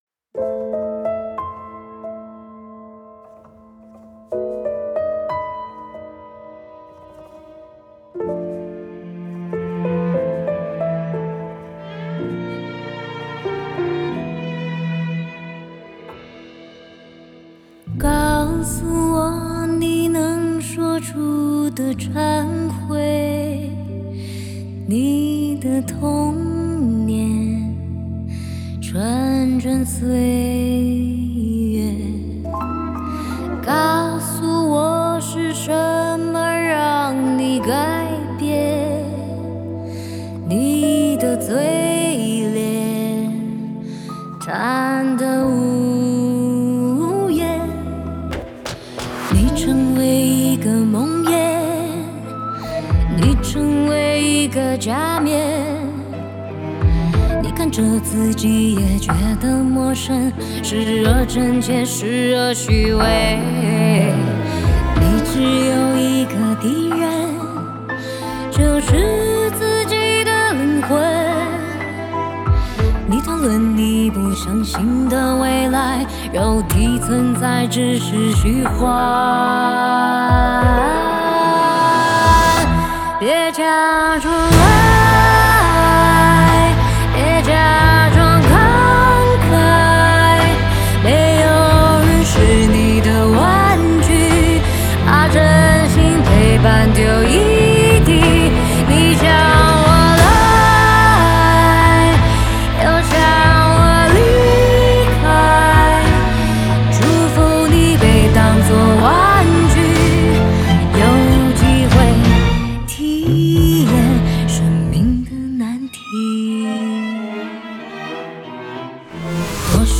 Ps：在线试听为压缩音质节选，体验无损音质请下载完整版
人声录音室：52HZ Studio
混音室：The mixHaus Studios, Los Angeles